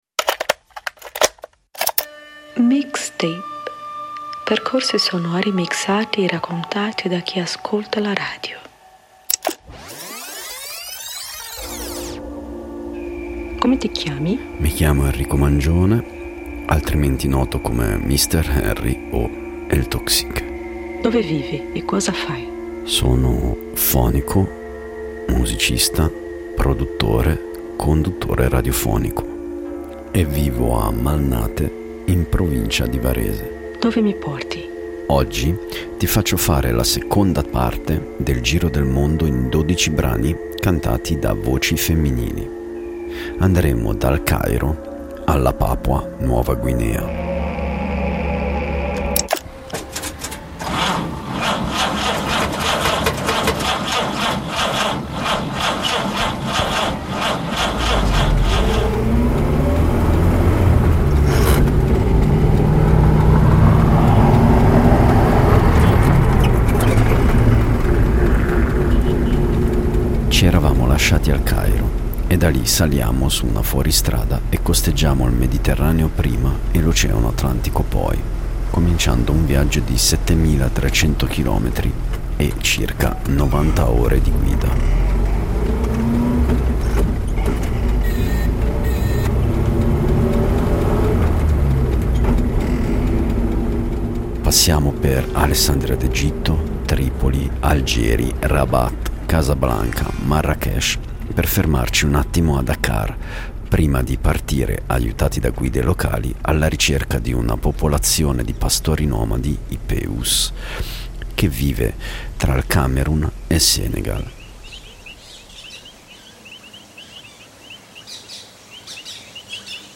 Alla scoperta dei canti tradizionali di Africa, sud-est asiatico e Oceania attraverso l'ascolto di preziosi field recordings e mitiche compilation degli anni 70: da una ragazza di una tribù di pastori nomadi nell'Africa occidentale (che vuole lasciare la famiglia) a due madri che cantano per i propri figli in Papua Nuova Guinea.
Koluba solo and duo with cicadas